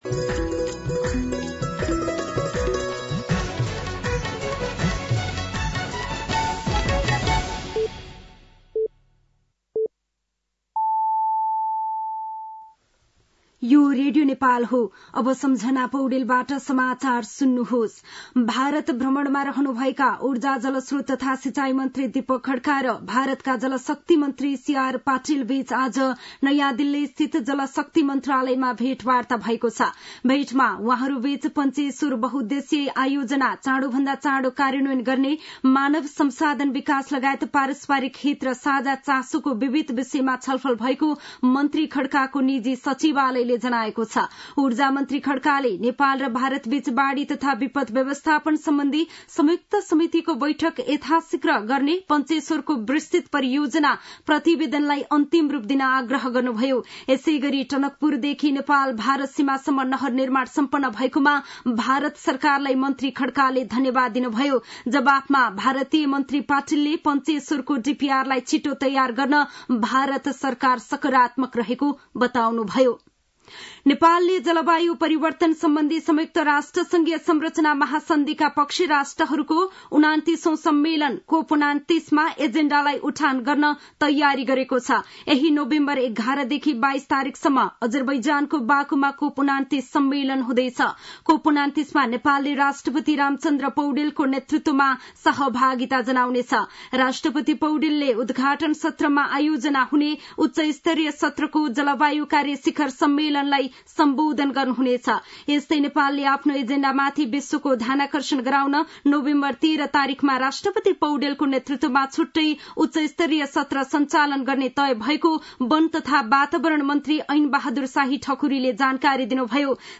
साँझ ५ बजेको नेपाली समाचार : २१ कार्तिक , २०८१